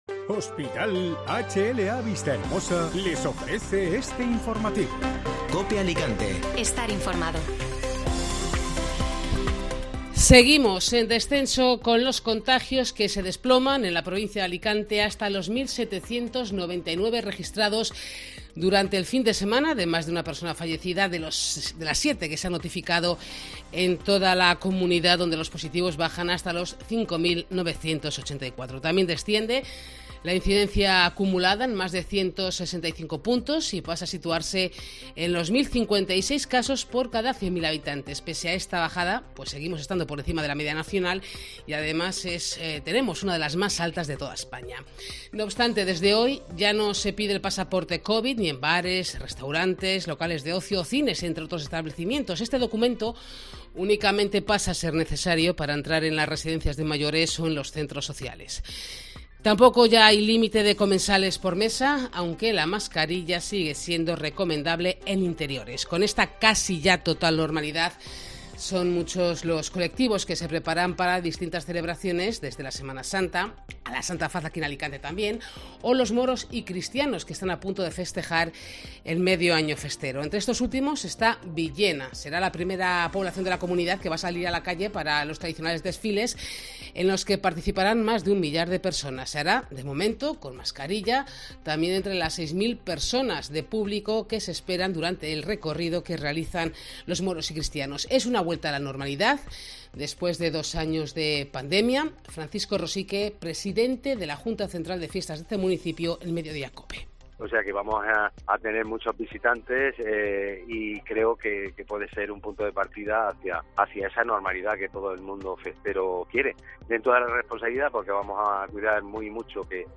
AUDIO: Escucha las noticias de este martes en Alicante y provincia